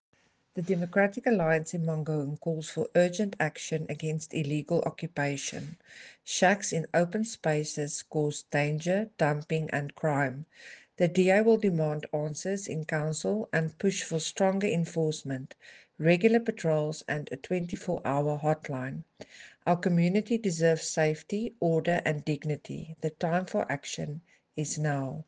Afrikaans soundbites by Cllr Selmé Pretorius and